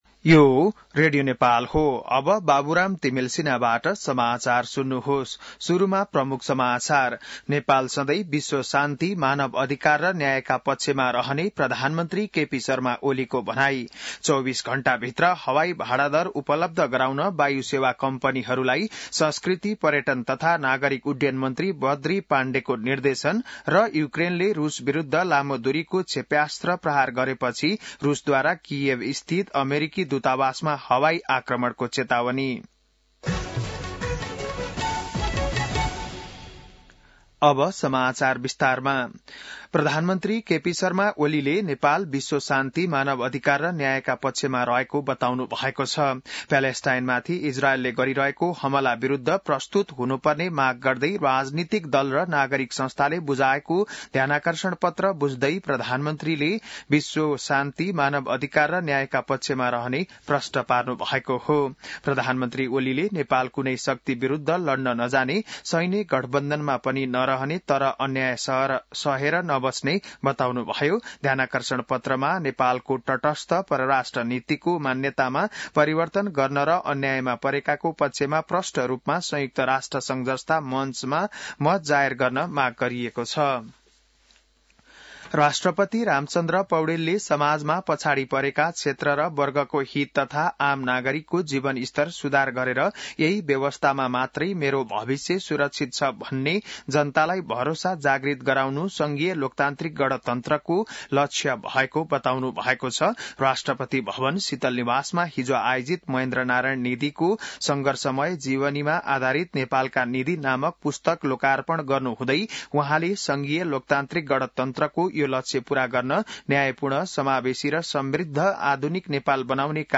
बिहान ९ बजेको नेपाली समाचार : ७ मंसिर , २०८१